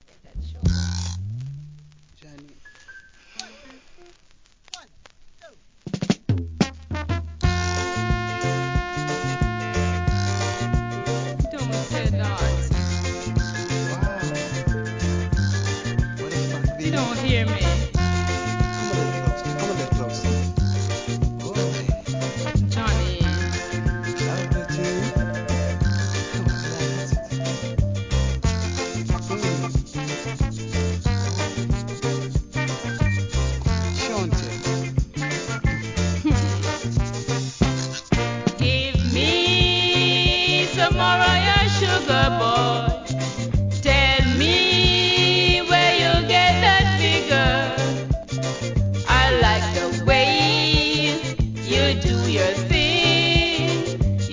REGGAE
伝統的な音作りでイナたいフィメール・ヴォーカル!